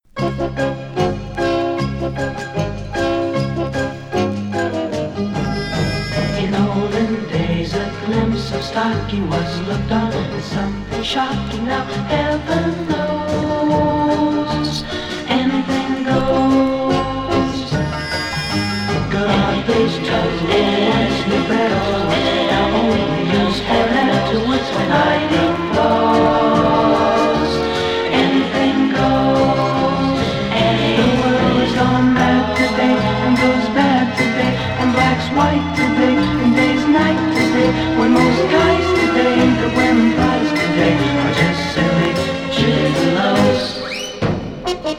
Rock, Pop, Soft Rock　USA　12inchレコード　33rpm　Stereo